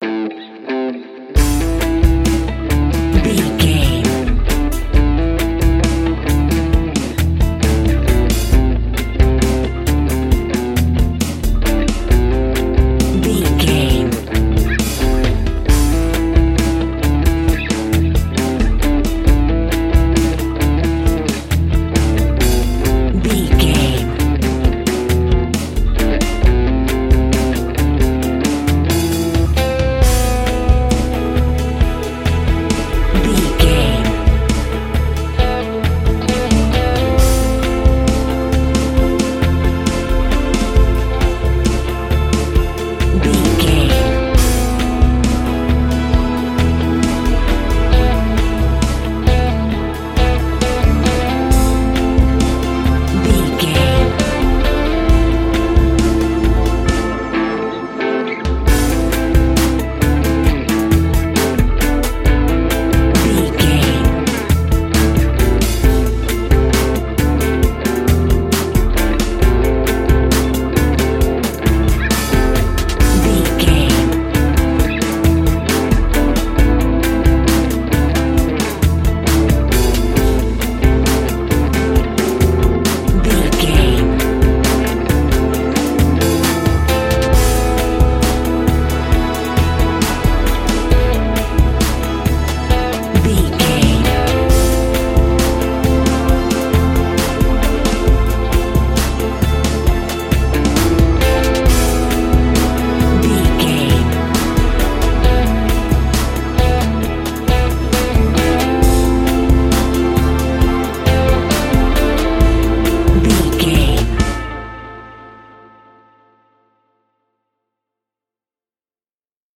Aeolian/Minor
drums
electric guitar
bass guitar